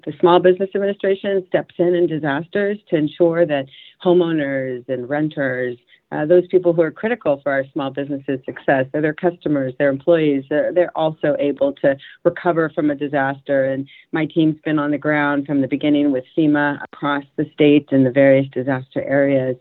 Isabel Casillas Guzman heads the U-S Small Business Administration and tells Radio Iowa that the S-B-A isn’t just devoted to helping small businesses recover.